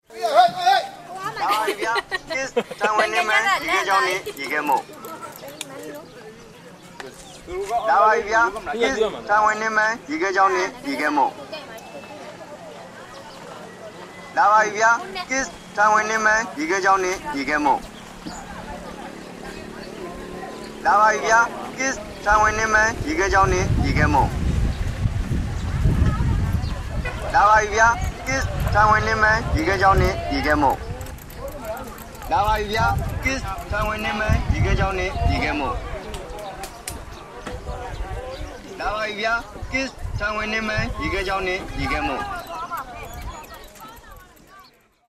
Ice cream vendor, Myanmar
An ice cream vendor selling his wares in Old Bagan, Myanmar.